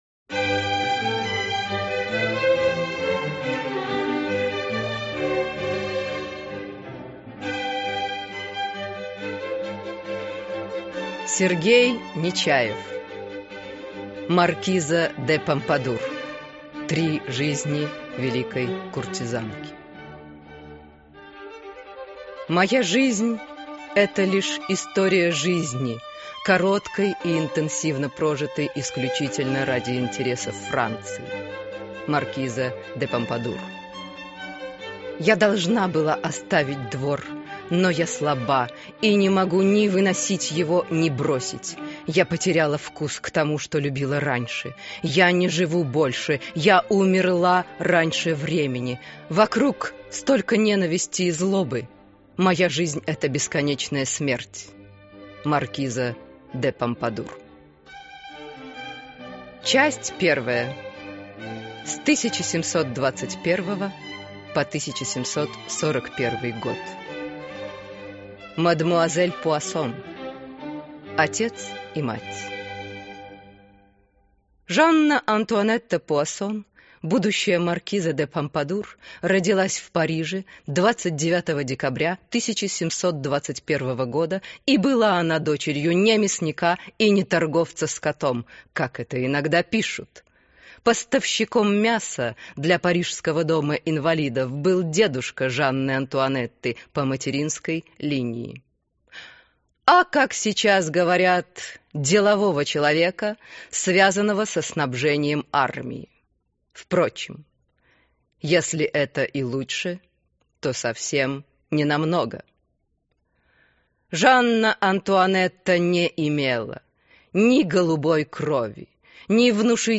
Аудиокнига Маркиза де Помпадур. Три жизни великой куртизанки | Библиотека аудиокниг